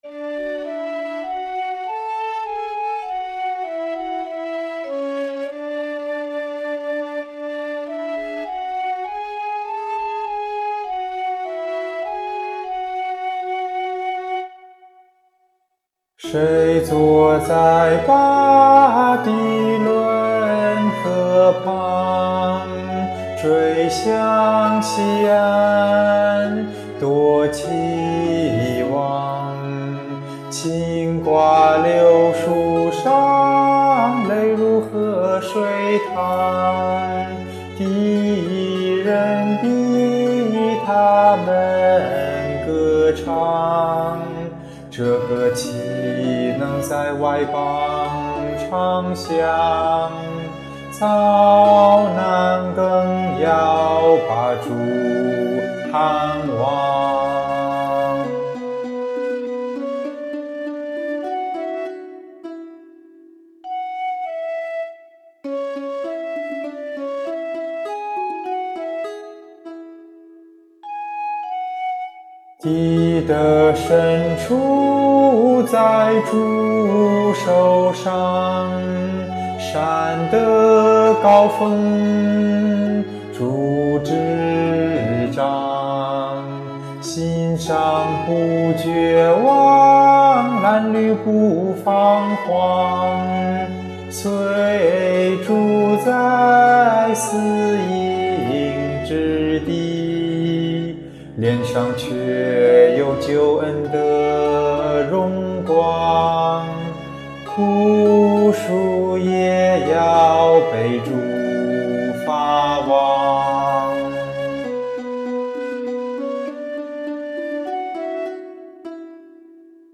献唱/赞美新歌《感恩的流离歌》